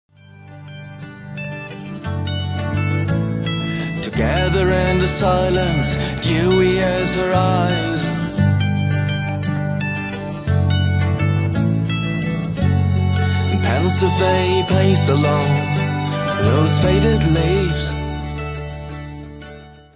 Celtic Rock